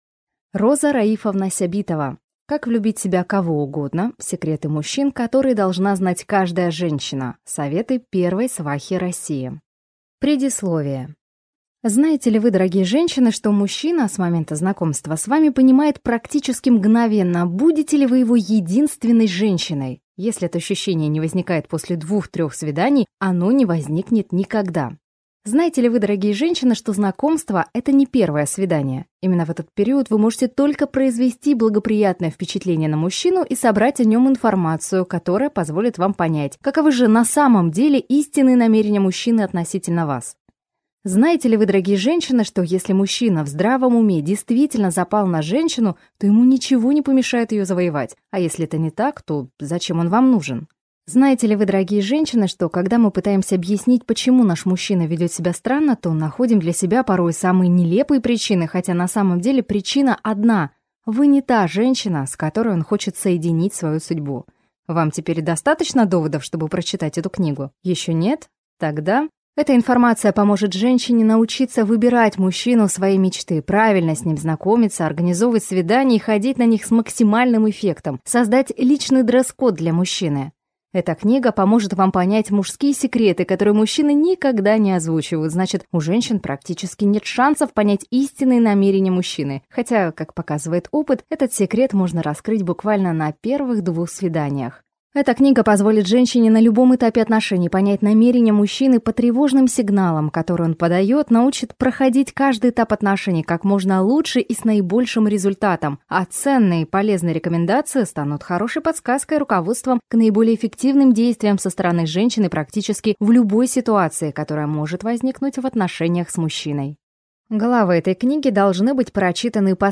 Аудиокнига Как влюбить в себя кого угодно. Секреты мужчин, которые должна знать каждая женщина | Библиотека аудиокниг